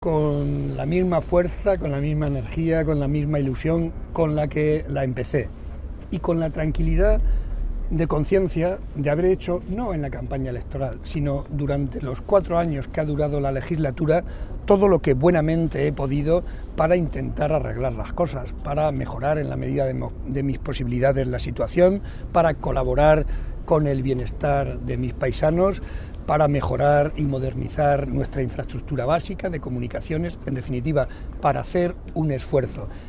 El presidente realizó estas declaraciones durante su visita al Club Deportivo Guadalajara, en el campo de fútbol Pedro Escarpín, donde aprovechó para desear lo mejor a este equipo al que animó para trabajar y lograr subir a Segunda División.
Cortes de audio de la rueda de prensa